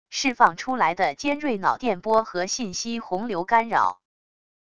释放出来的尖锐脑电波和信息洪流干扰wav音频